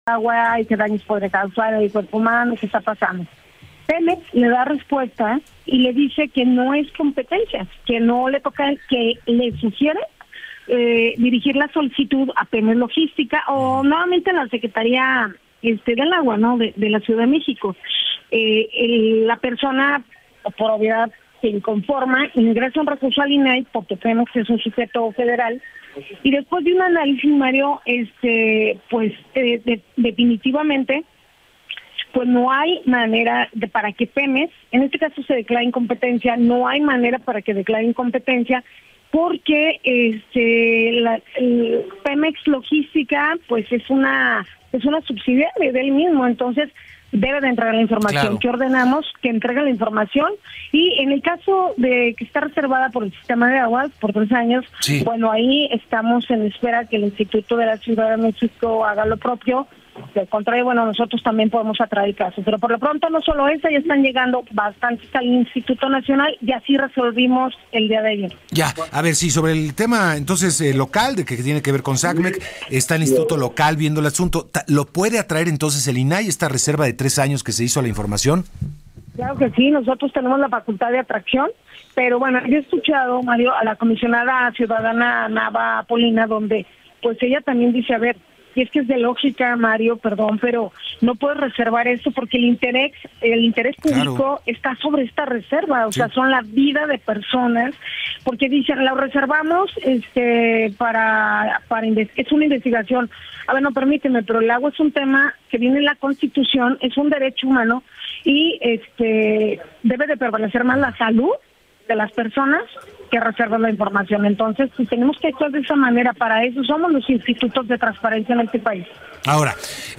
Entrevista en Enfoque Noticias - julietadelrio